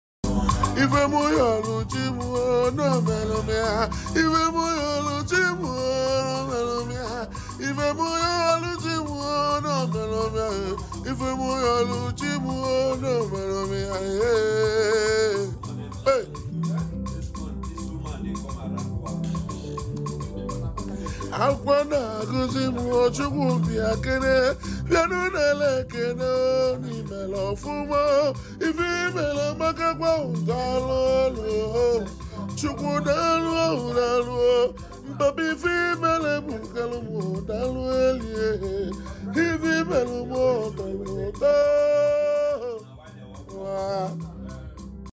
a renowned Nigerian highlife singer